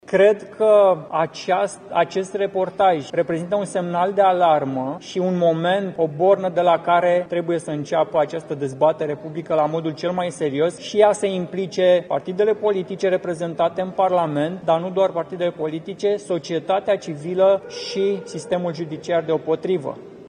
În cadrul unei conferințe de presă – în care USR l-a chemat pentru explicații în fața plenului Parlamentului pe actualul ministru al Justiției, Radu Marinescu -, deputatul a explicat că ar trebui să fie deschis un dosar de cercetare.